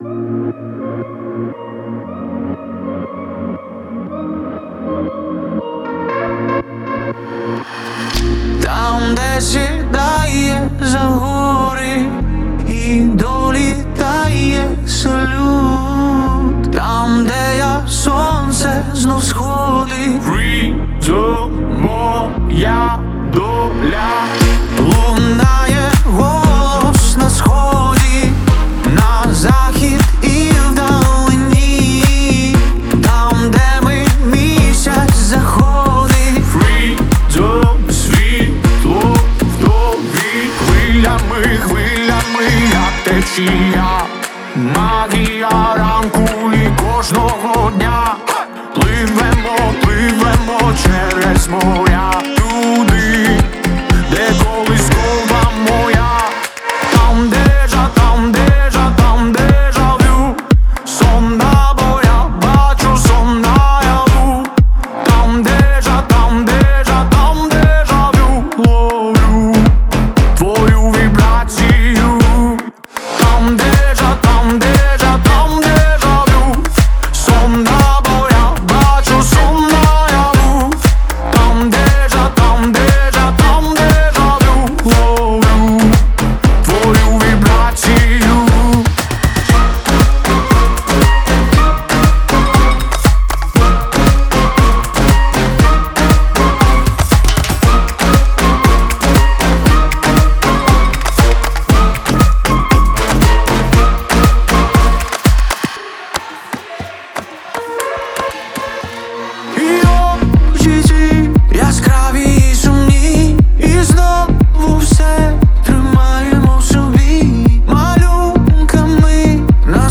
UA Version - Remix
Плюсовий запис